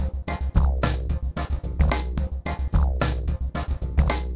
RemixedDrums_110BPM_28.wav